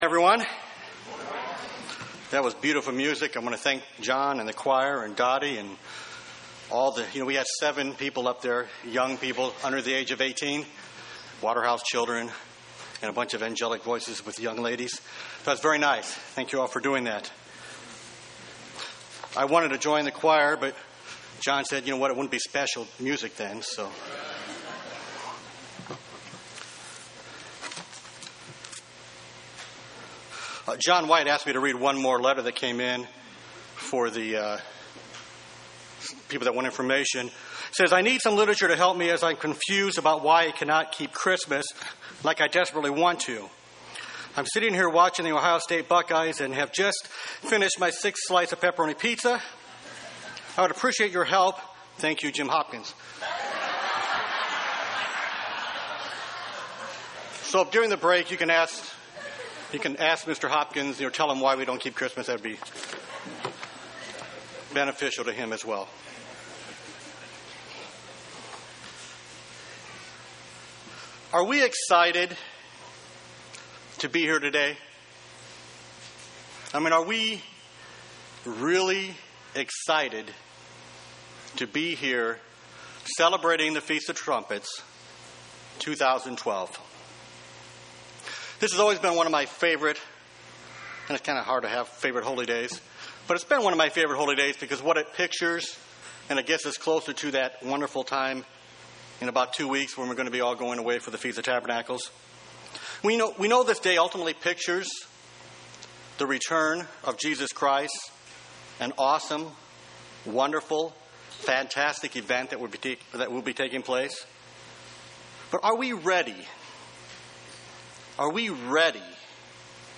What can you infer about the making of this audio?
Given in Columbus, OH